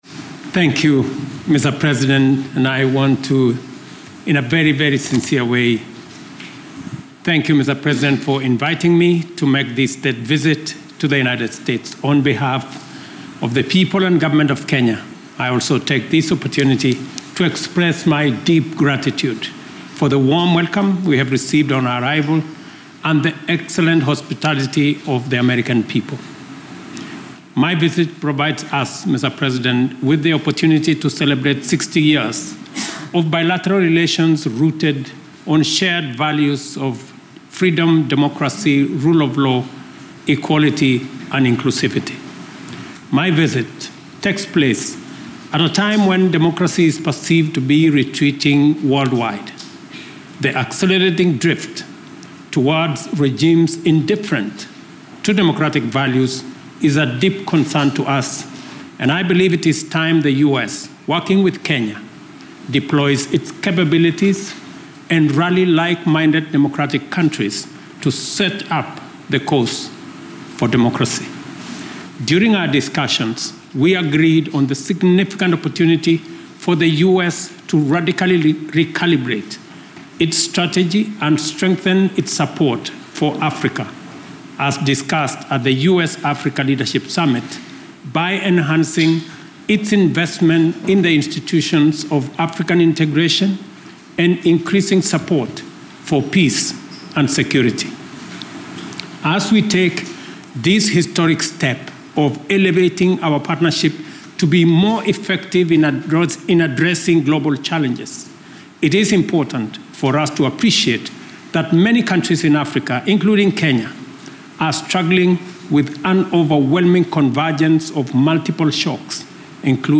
Opening Remarks at a White House Joint Presser with U.S. President Joseph R. Biden
delivered 23 May 2024, East Room, White House, Washington, D.C.
Audio Note: AR-XE = American Rhetoric Extreme Enhancement